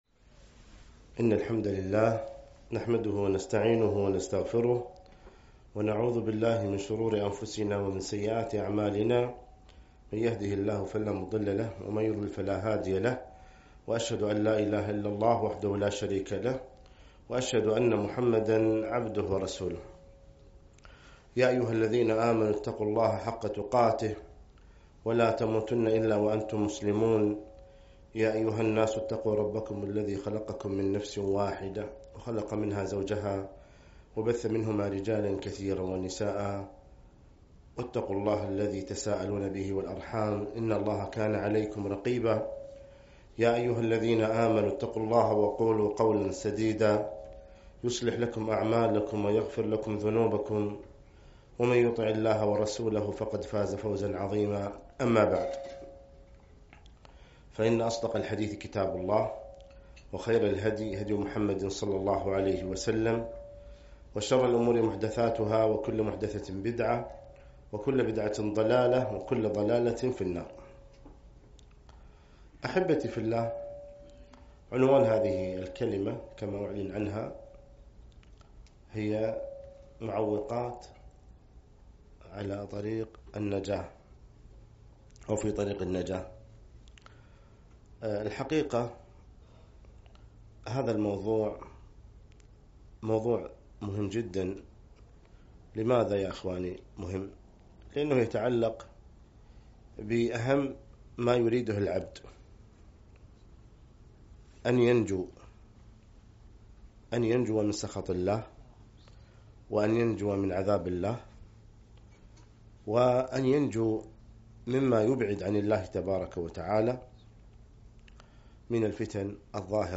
محاضرة - معوقات في طريق النجاة